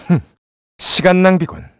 voices / heroes / kr
Riheet-Vox_Skill3_kr.wav